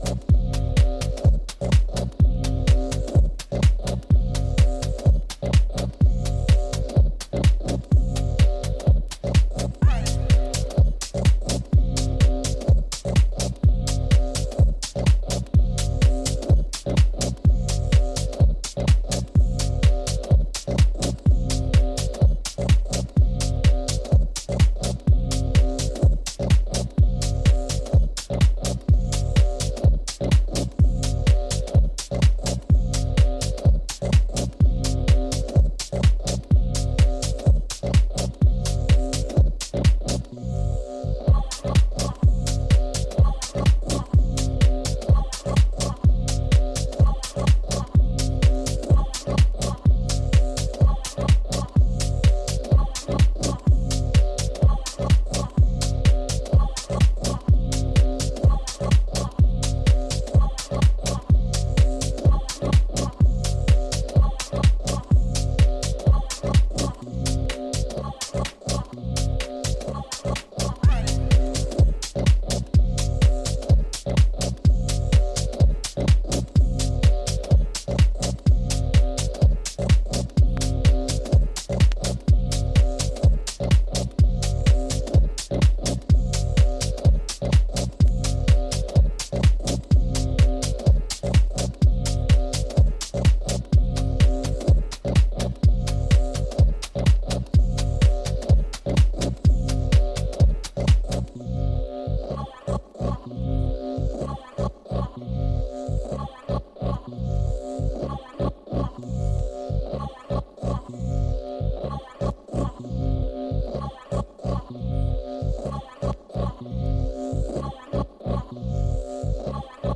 いずれの楽曲も、巧妙なインテリジェンスを感じさせるテクスチャーが構築されたアブストラクトなミニマル・ハウスといった趣。